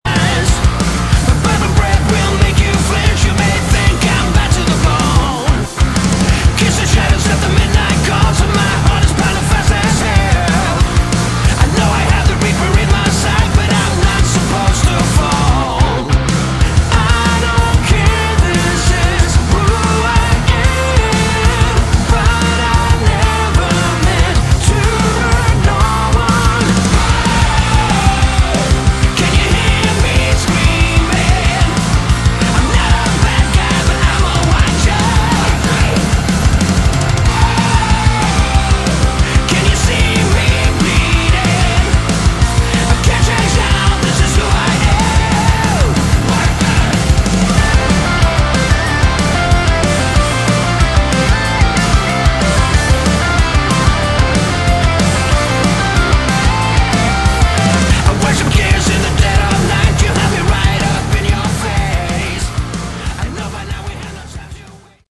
Category: Melodic Rock
Vocals, Bass
Drums
Keyboards
Guitars